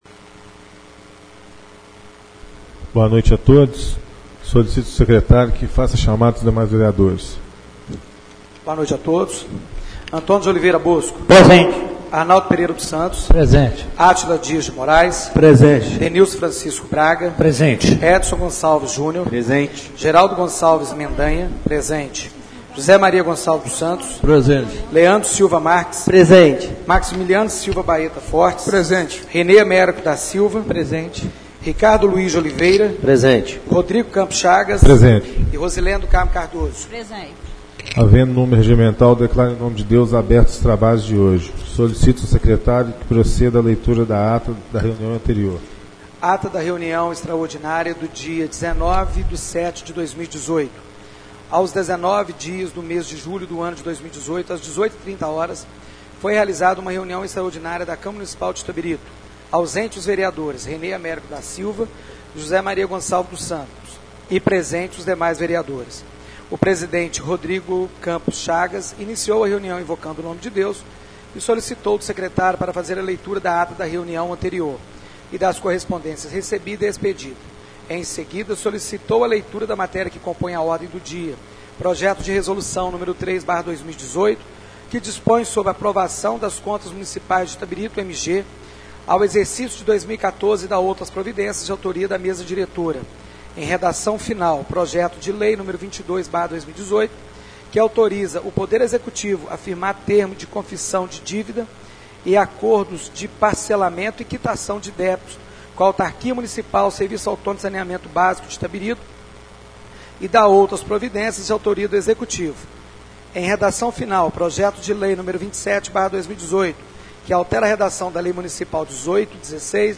Reunião Ordinária do dia 06/08/2018